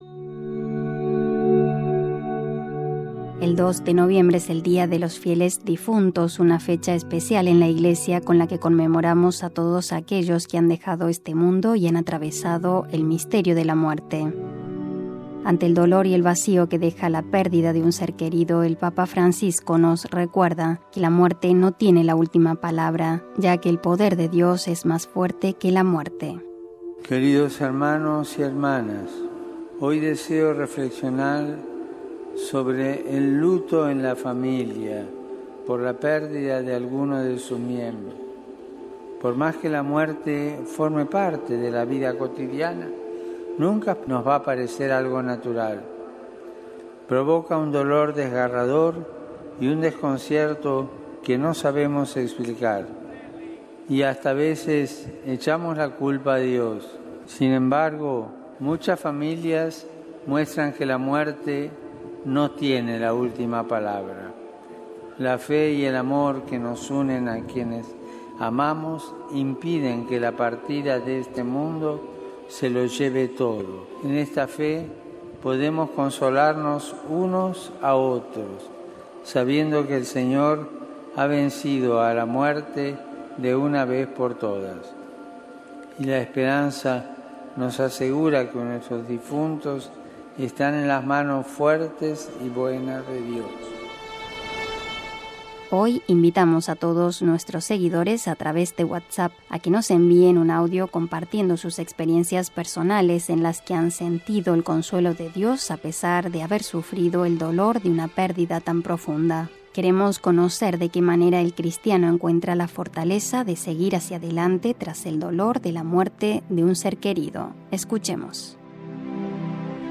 Hoy invitamos a todos nuestros seguidores a través de WhatsApp y las Redes Sociales, a que nos envíen mensajes de audio compartiendo sus experiencias personales, en las que han sentido el consuelo de Dios a pesar de haber sufrido el dolor de una pérdida tan profunda. ¿De qué manera el cristiano encuentra la fortaleza de seguir hacia adelante tras el dolor de la muerte de un ser querido?